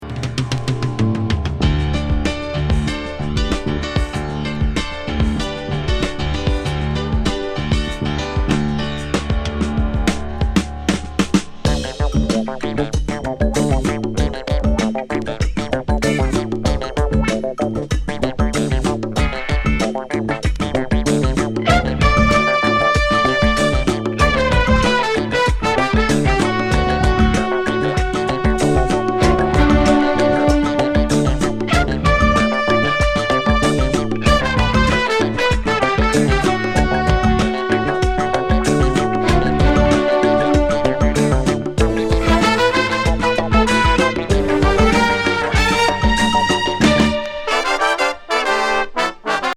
ファンク・ディスコ!